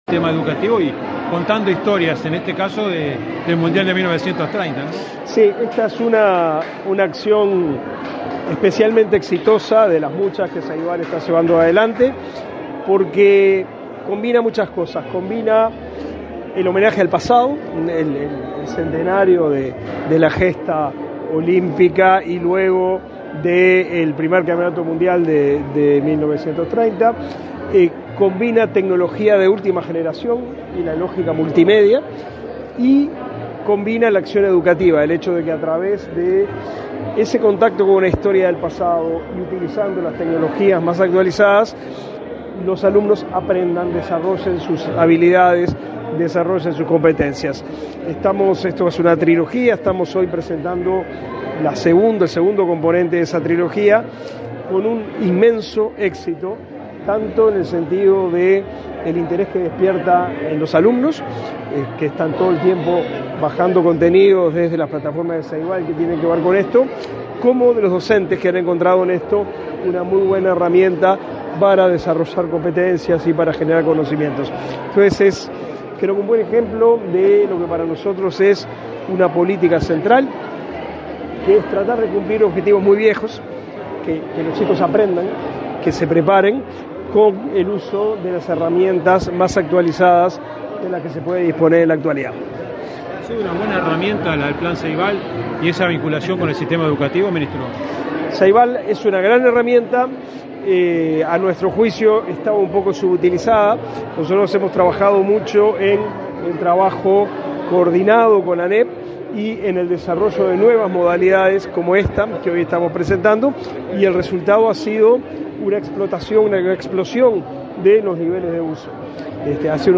Declaraciones a la prensa del ministro de Educación y Cultura, Pablo da Silveira